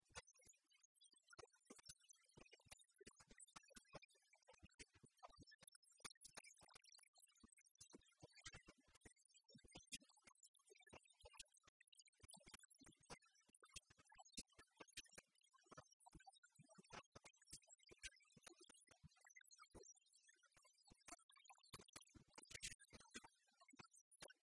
Invitat de Ovidiu Ioanițoaia la Europa FM, în emisiunea “Tribuna Zero”, fostul mijlocaș al Stelei a spus că va intra cu siguranță în turul al 2-lea, iar favorit ar fi, deocamdată, actualul președinte, Răzvan Burleanu: